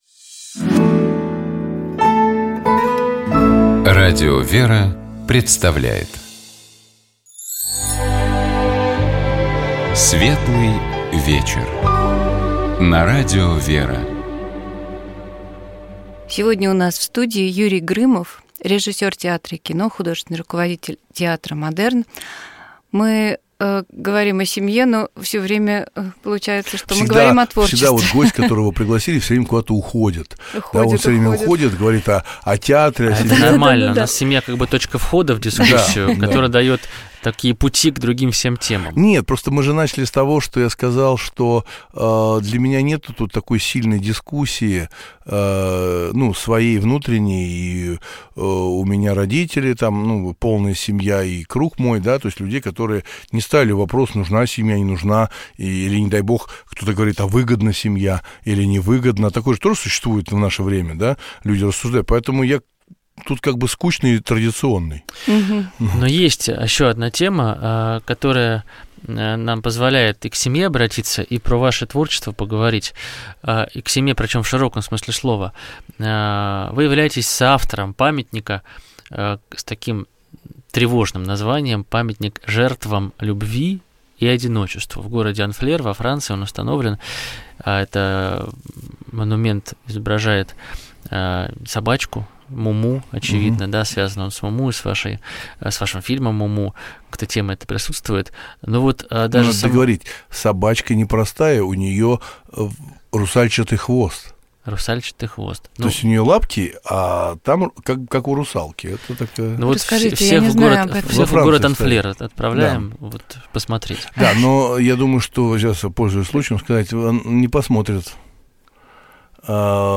У нас в гостях был режиссер театра и кино, художественный руководитель театра Модерн Юрий Грымов.